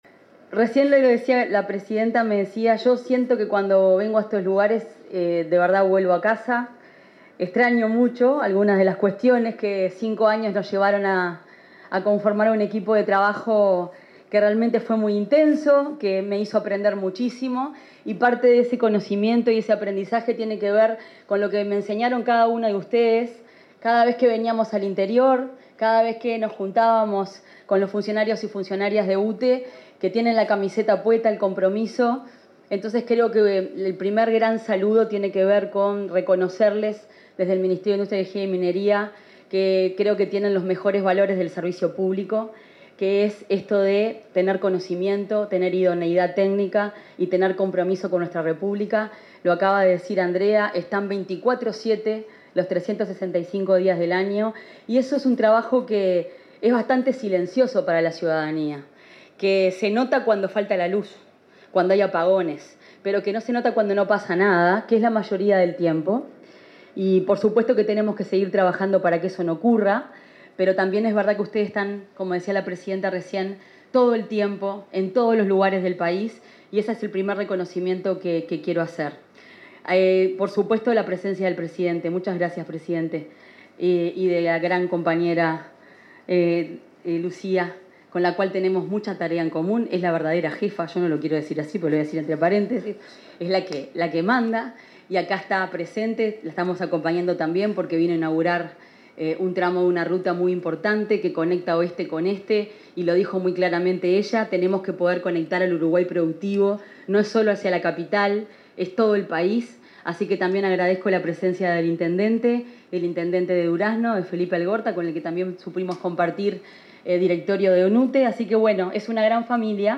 Palabras de la ministra de Energía, Fernanda Cardona
Palabras de la ministra de Energía, Fernanda Cardona 04/12/2025 Compartir Facebook X Copiar enlace WhatsApp LinkedIn En el 80.° aniversario de la central hidroeléctrica Rincón del Bonete, ubicada en Paso de los Toros, Tacuarembó, hizo uso de la palabra la ministra de Industria, Energía y Minería, Fernanda Cardona.